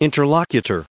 added pronounciation and merriam webster audio
1621_interlocutor.ogg